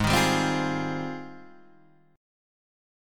G#M13 chord